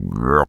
frog_deep_croak_03.wav